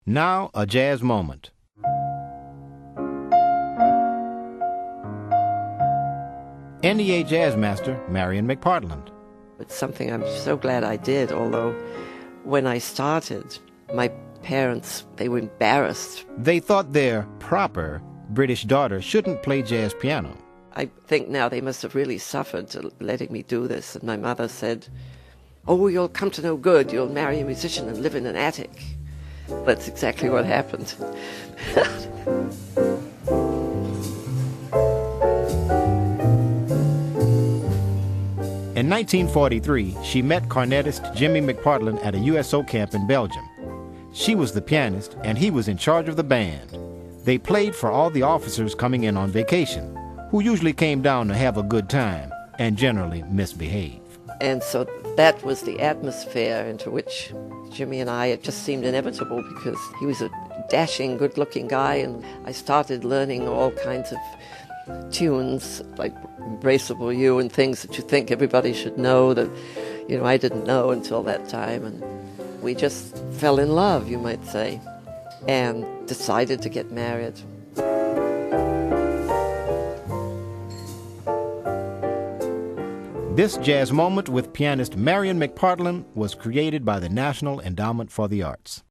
Music: "It Never Entered My Mind," CD: Windows, Concord CCD2-2233-2, (disc 1, cut2)
This Jazz Moment with pianist Marian McPartland was created by the National Endowment for the Arts.